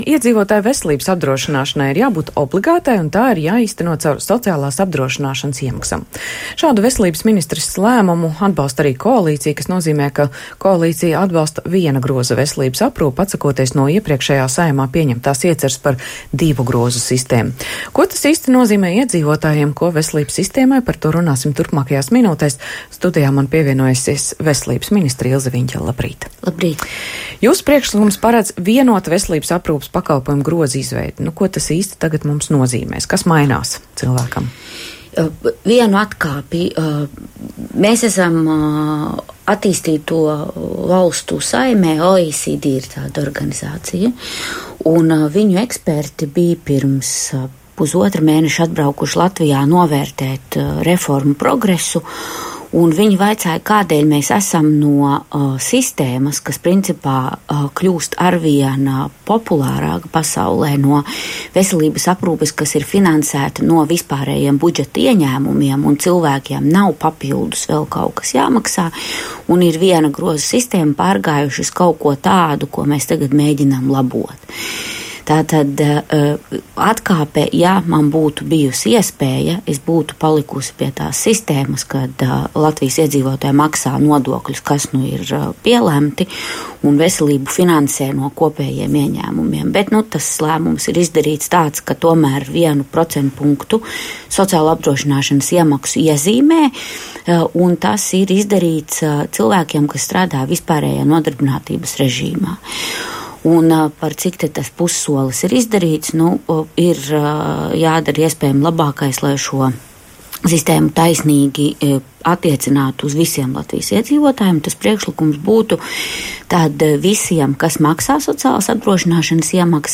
Veselības ministrija iecerējusi rosināt izmaiņas vairākos normatīvos, lai uzlabotu zāļu pieejamību un samazinātu izdevumus par zālēm pacientiem, Latvijas Radio teica veselības ministre Ilze Viņķele. Viņa skaidroja, ka tiks veikti vairāki grozījumi, kas atstās ietekmi gan uz medikamentu cenām, gan nodrošinās brīvāku un godīgāku konkurenci medikamentu tirgū.